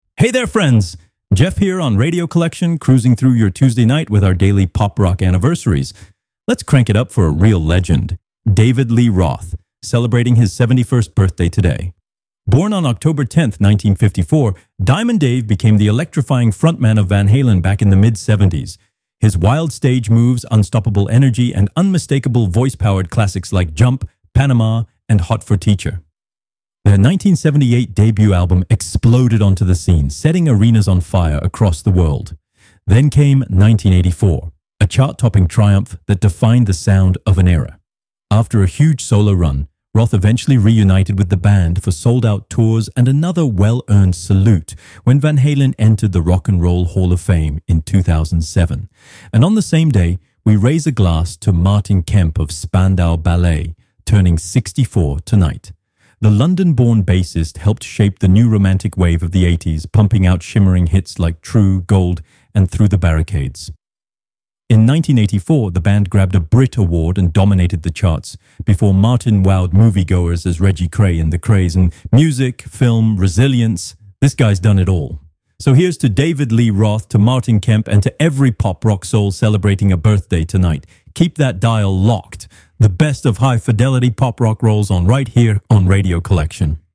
You're listening to the Pop Rock column on Radio Collection, the free, ad-free web radio station that broadcasts the greatest classics and new releases in Hi-Fi quality.